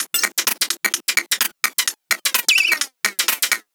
VEH1 Fx Loops 128 BPM
VEH1 FX Loop - 17.wav